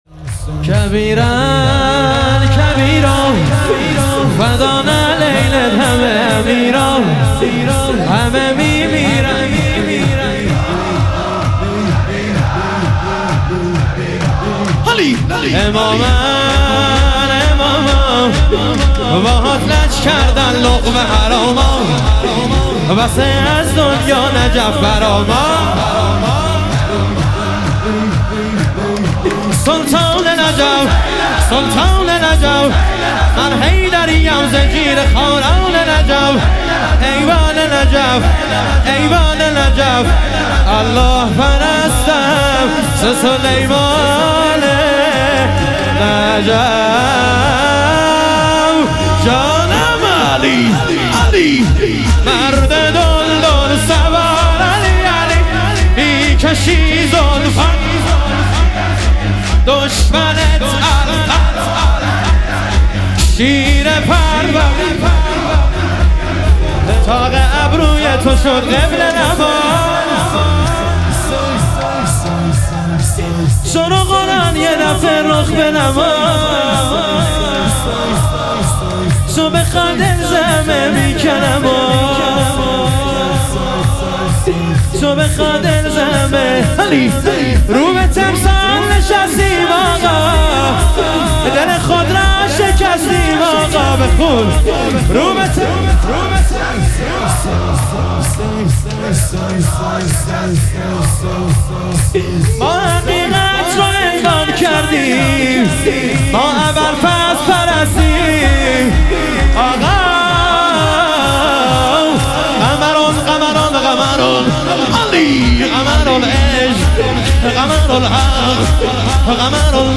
شهادت حضرت مسلم علیه السلام - شور